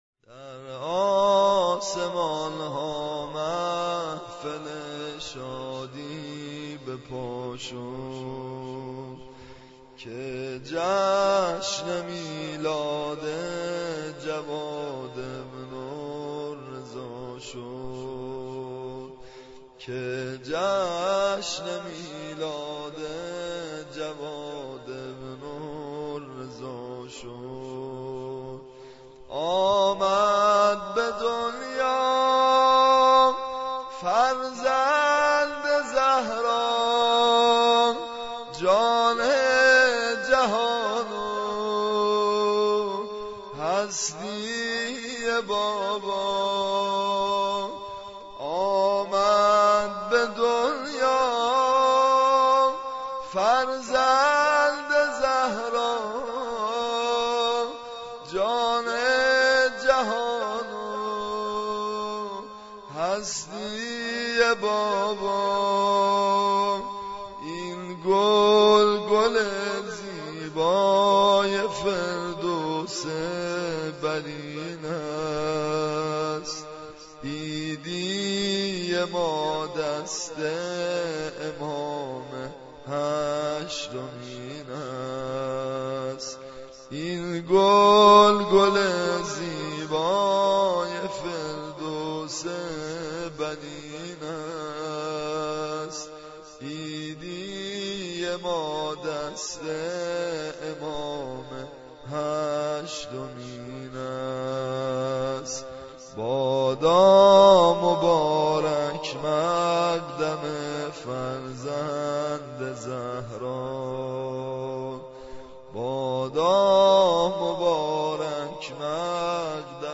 سرود (مسجدی)